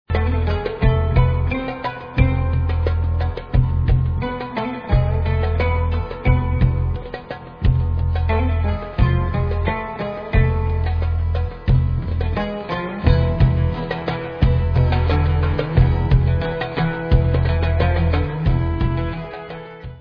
Celtic-worldbeat-folk-pop//special box